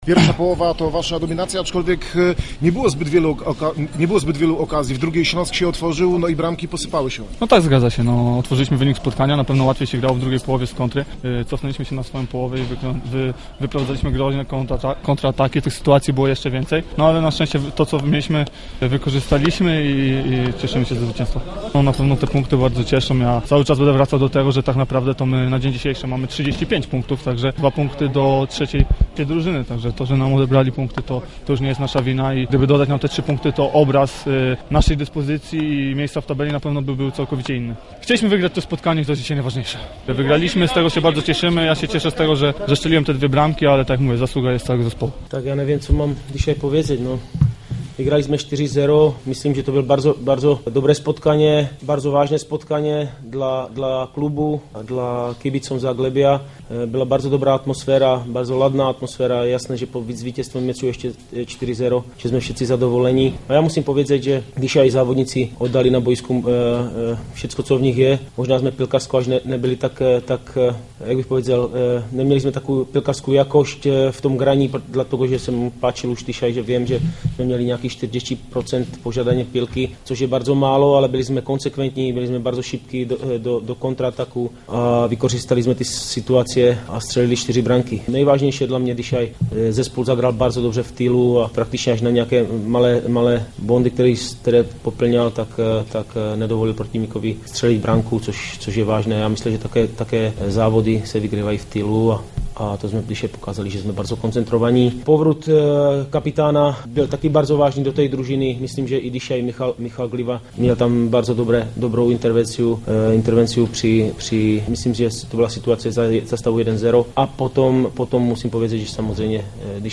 trener Zagłębia, Pavel Hapal